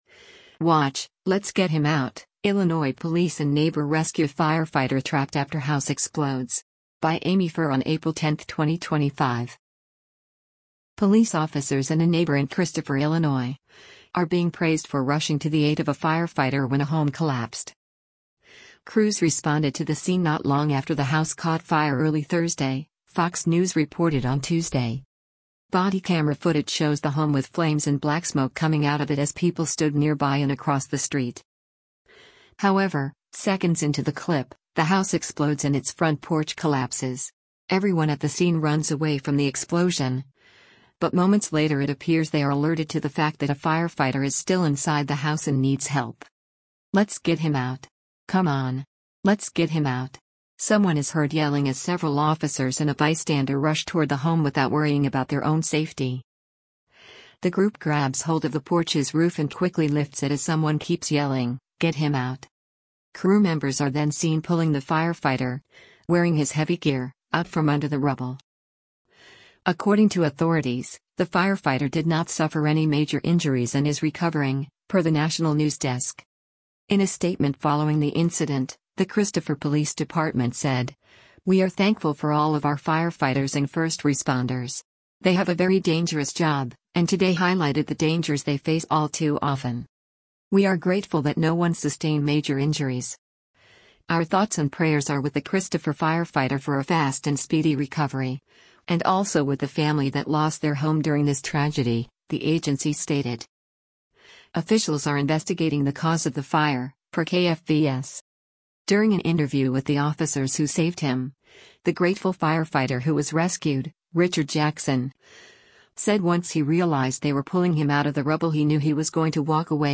Body camera footage shows the home with flames and black smoke coming out of it as people stood nearby and across the street.
However, seconds into the clip, the house explodes and its front porch collapses.
“Let’s get him out! Come on! Let’s get him out!” Someone is heard yelling as several officers and a bystander rush toward the home without worrying about their own safety.
The group grabs hold of the porch’s roof and quickly lifts it as someone keeps yelling, “Get him out!”